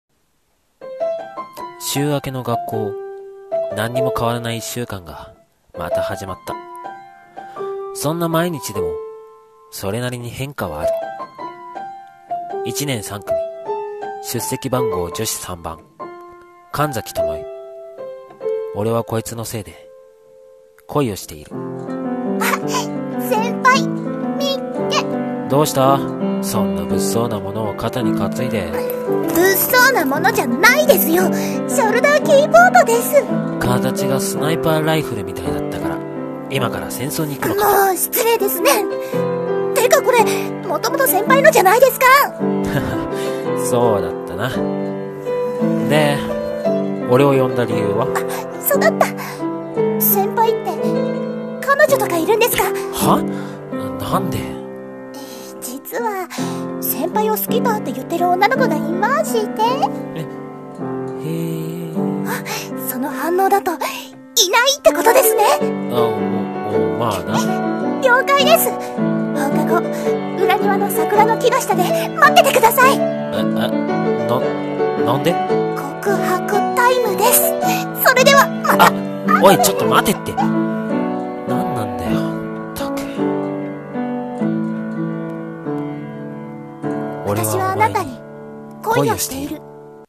台本【アオハル♡シュガー】2人声劇 コラボ